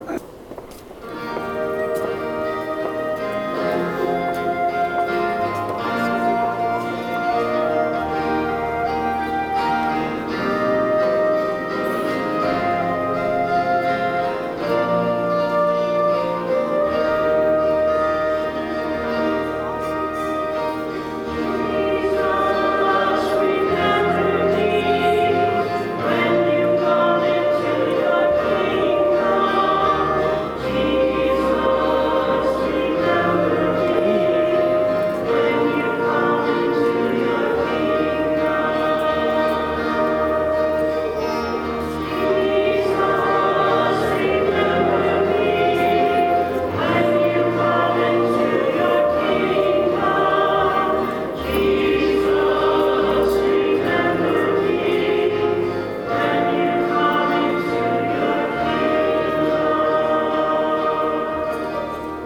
Music from the 10:30 Mass on the 1st Sunday in Lent, March 24, 2013: